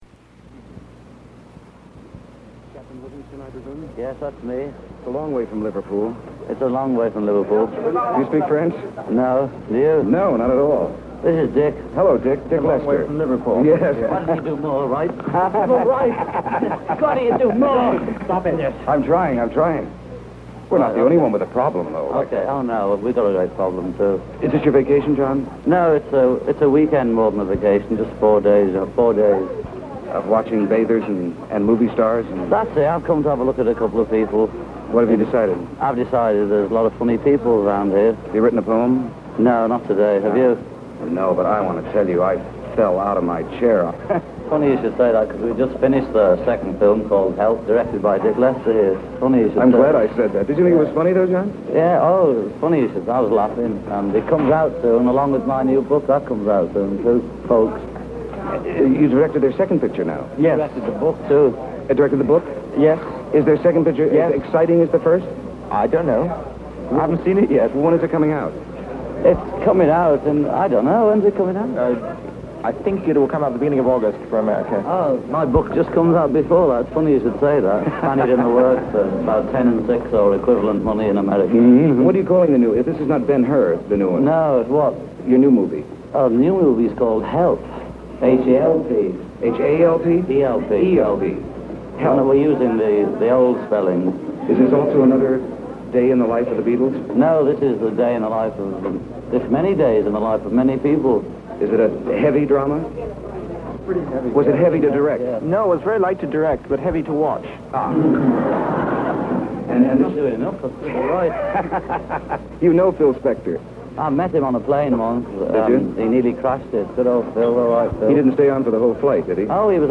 Merv Griffin interviews John Lennon and Richard Lester on May 25, 1965, in Cannes, France.